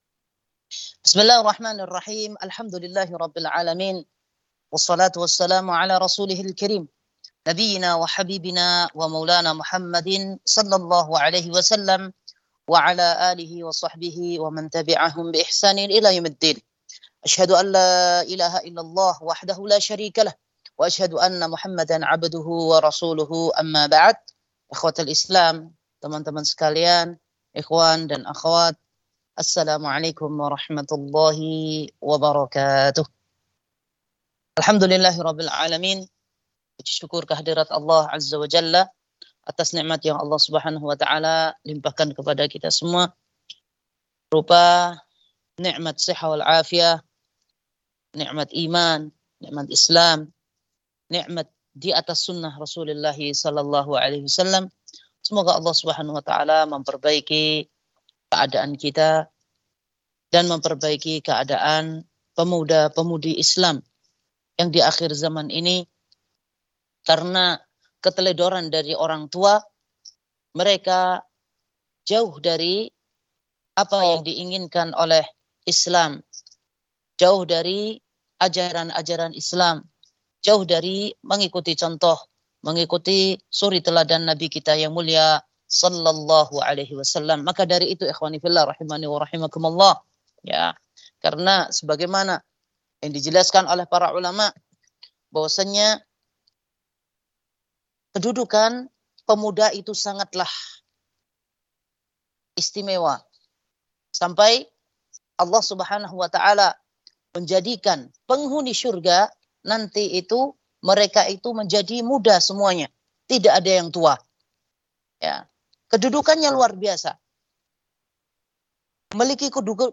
Kajian Online Dzulhijjah – Teams Awqaf Wakra